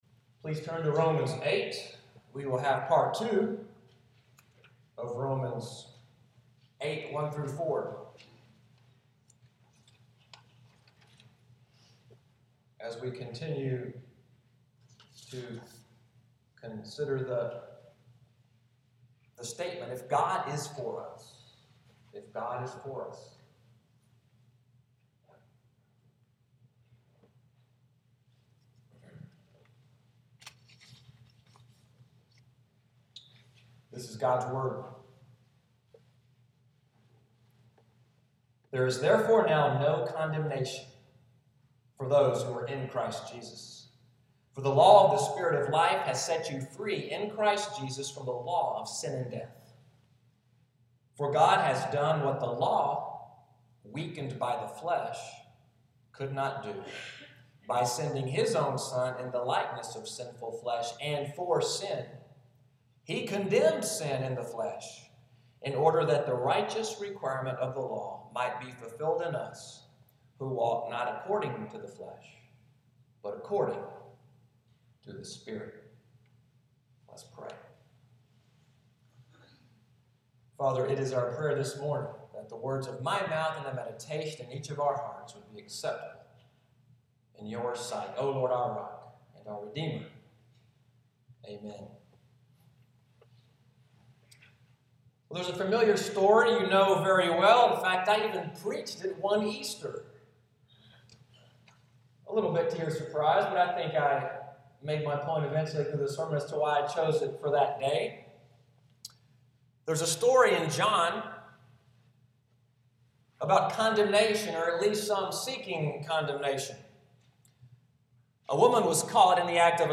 Sunday’s sermon, (audio) “Saved for Holiness,” June 7, 2015